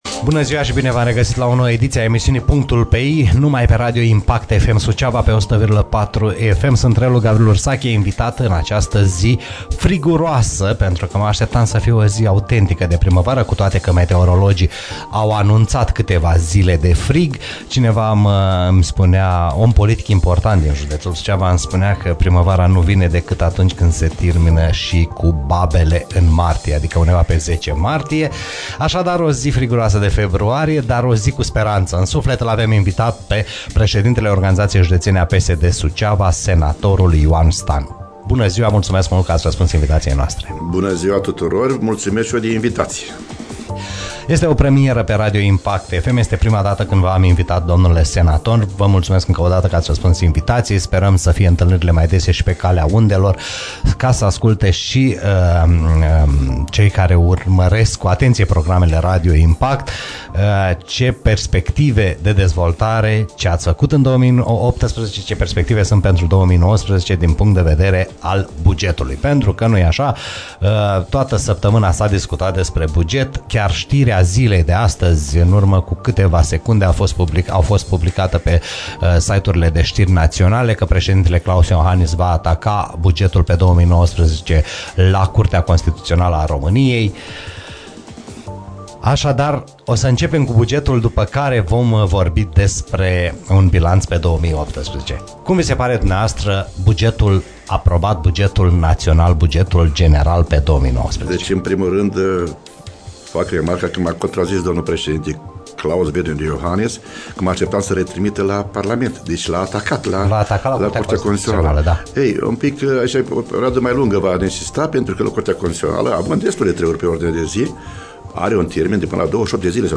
Senatorul Ioan Stan live la PUNCTUL PE I